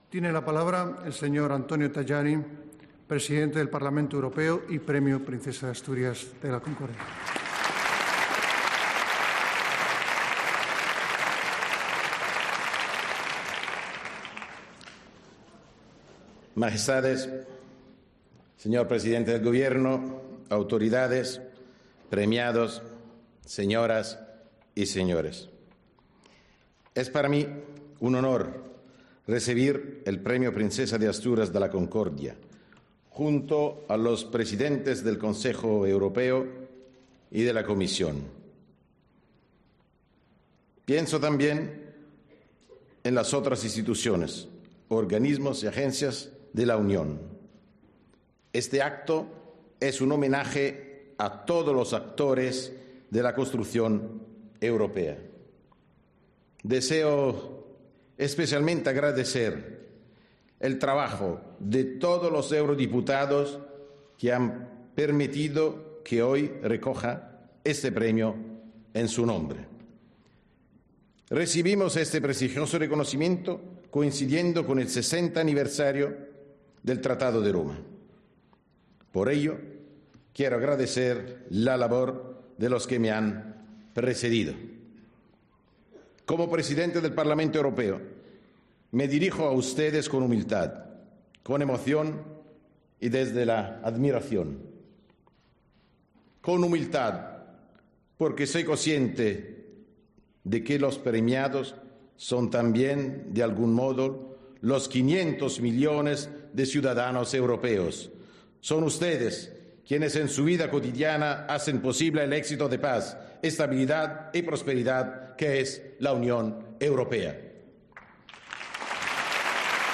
Escucha el discurso de Antonio Tajani en la ceremonia de entrega de los Premios Princesa de Asturias.